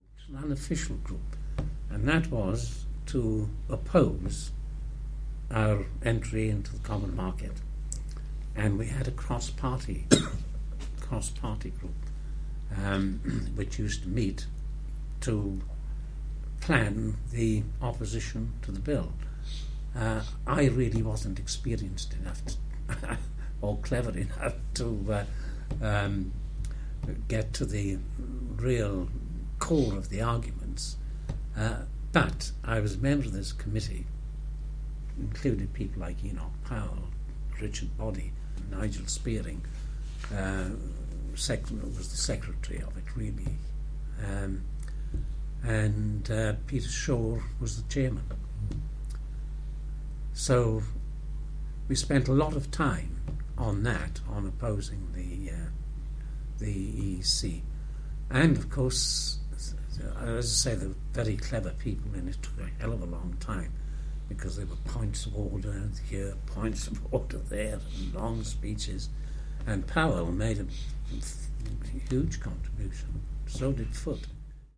The issue features prominently in our interviews with former MPs for our oral history archive.
David Stoddart, MP for Swindon who opposed joining, remembered working directly with the Conservatives to oppose legislation: